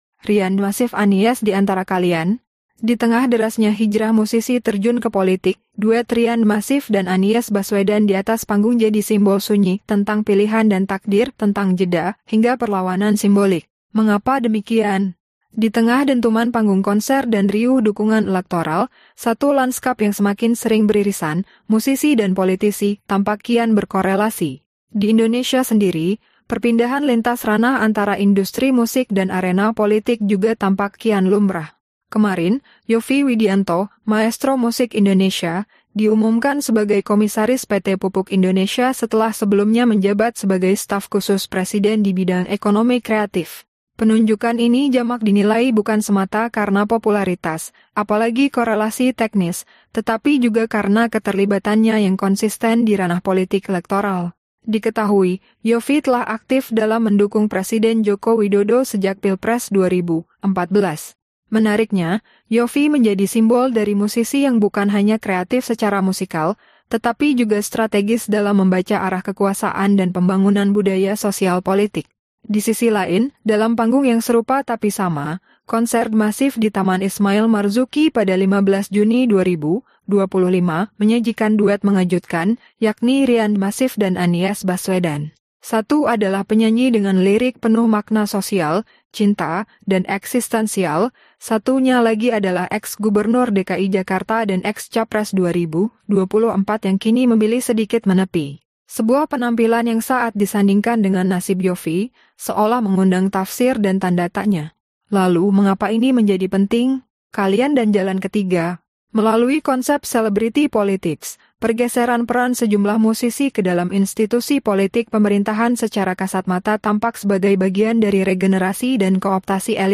Este audio se realiza usando AI.